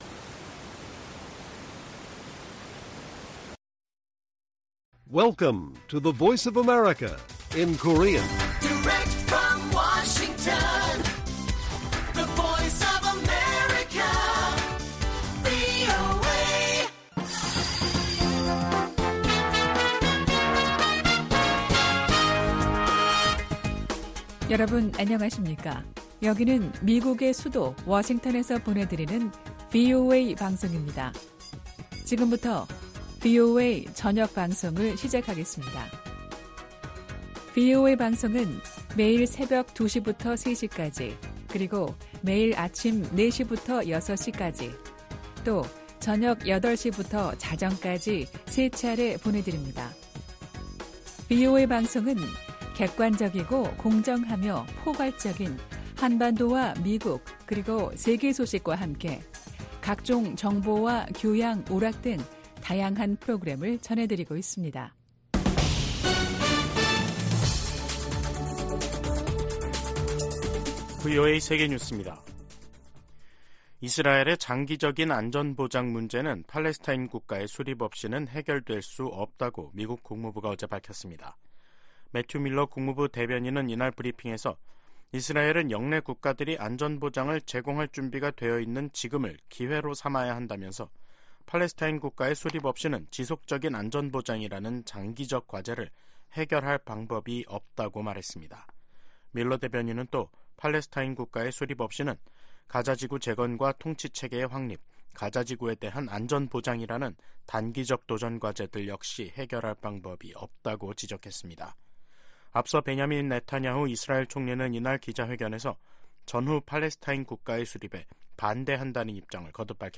VOA 한국어 간판 뉴스 프로그램 '뉴스 투데이', 2024년 1월 19일 1부 방송입니다. 북한이 수중 핵무기 체계 '해일-5-23'의 중요 시험을 동해 수역에서 진행했다고 발표했습니다. 유엔 안전보장이사회가 새해 들어 처음 북한 관련 비공개 회의를 개최한 가운데 미국은 적극적인 대응을 촉구했습니다. 최근 심화되는 북러 군사협력으로 향후 10년간 북한의 역내 위협 성격이 급격하게 바뀔 수 있다고 백악관 고위 당국자가 전망했습니다.